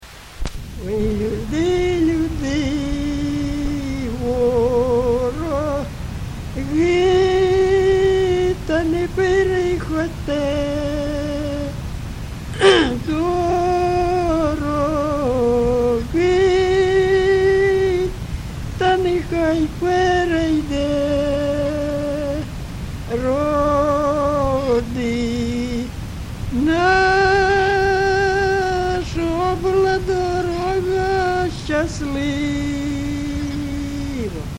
ЖанрВесільні
Місце записус. Єлизаветівка, Лозівський район, Харківська обл., Україна, Слобожанщина